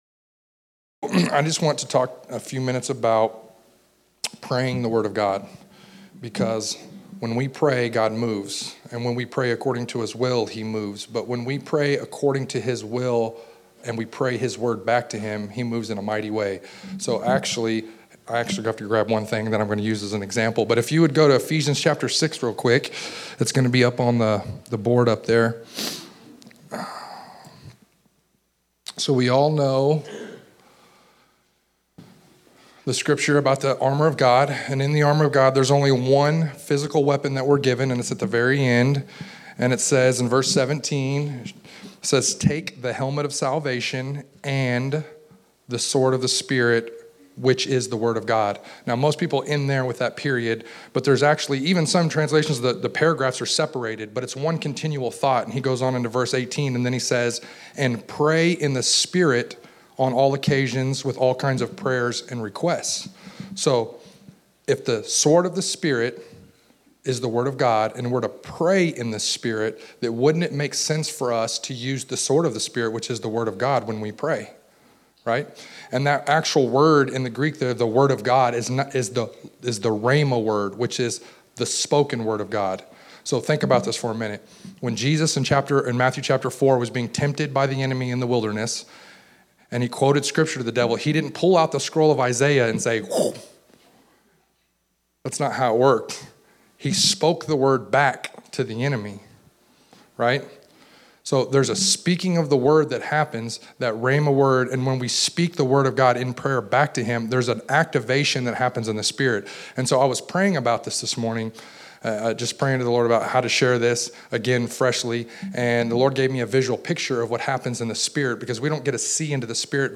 November 20, 2016      Category: Primers      |      Location: El Dorado